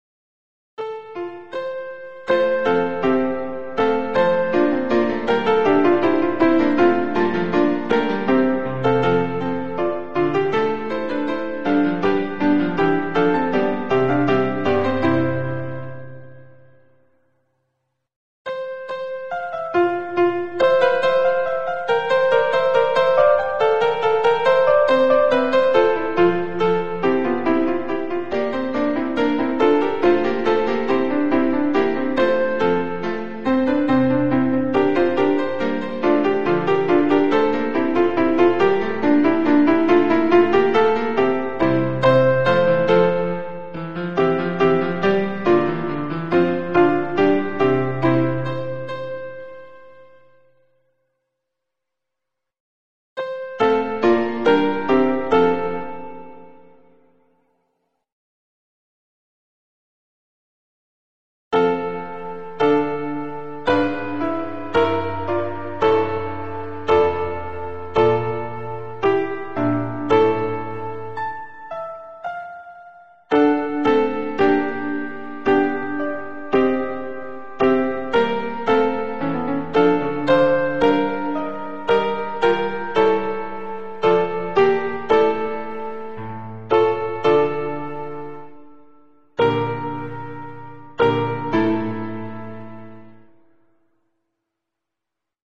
MIDI
Sans paroles
Voix + chœur en sourdine